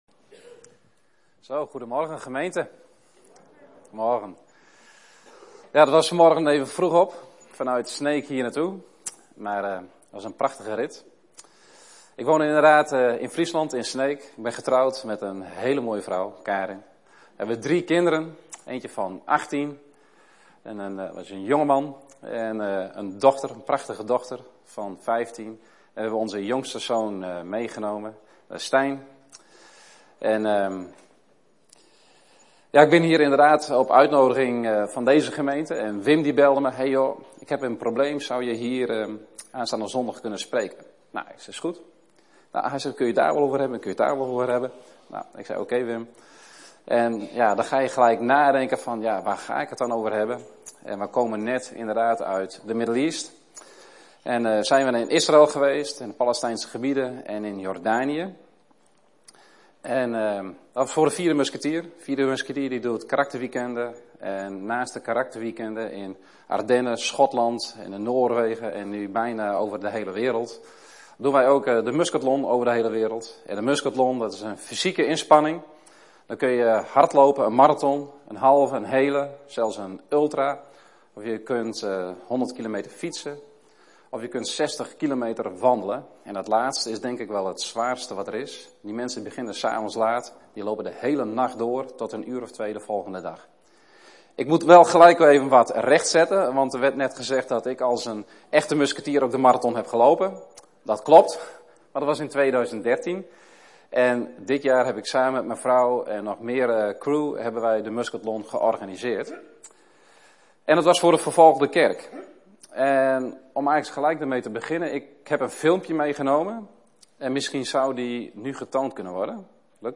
Preek
We komen elke zondagmorgen bij elkaar om God te aanbidden.